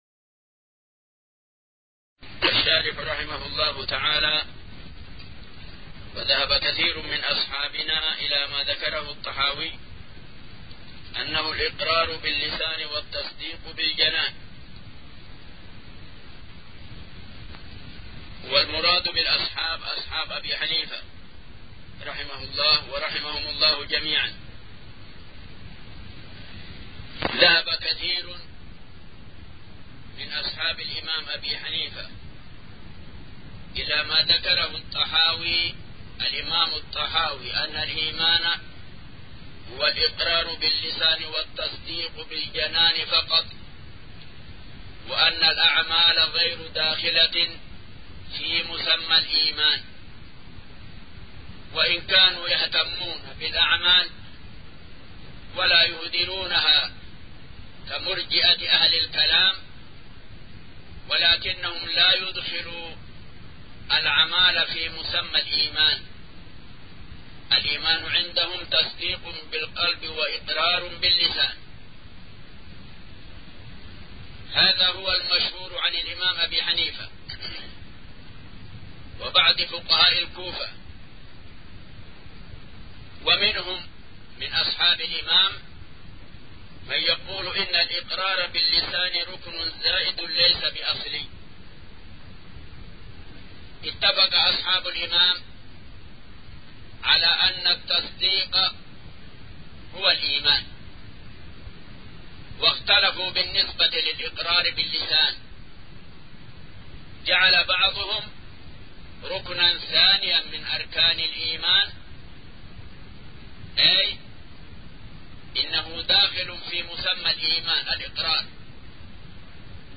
شرح العقيدة الطحاوية الدرس الأربعون